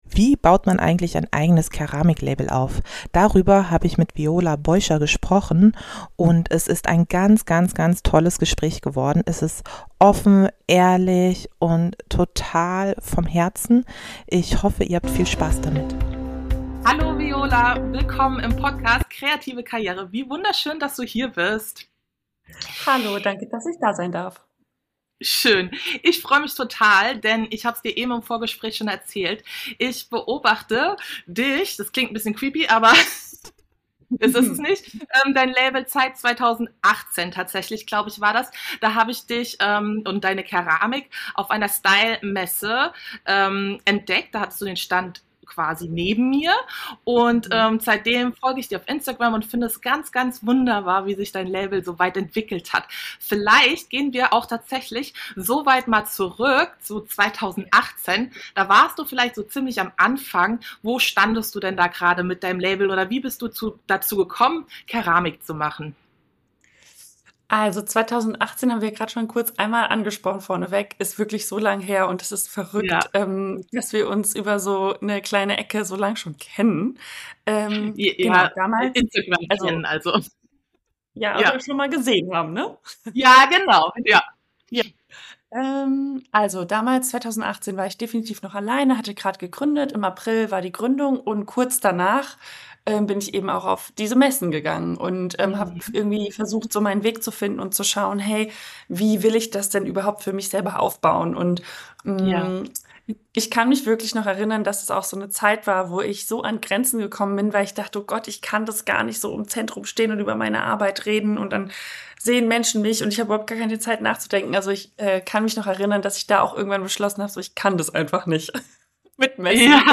25 - Interview